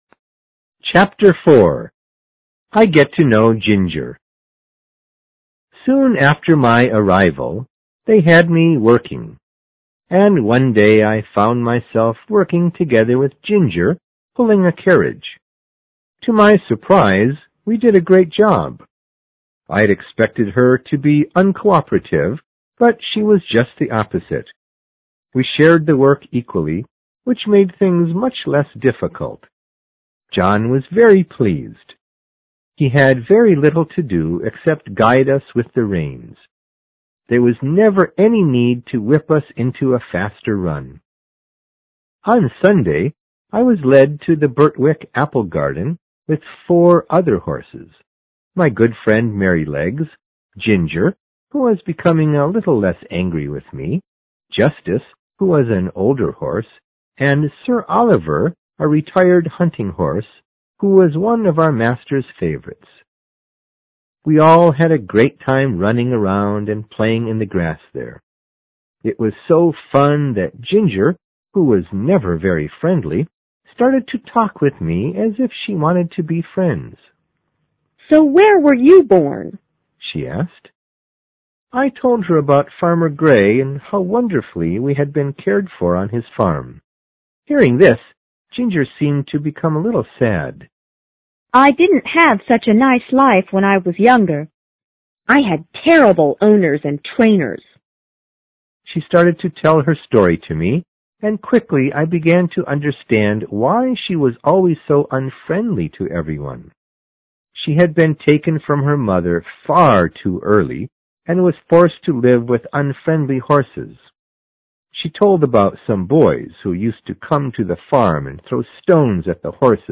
有声名著之黑骏马04 听力文件下载—在线英语听力室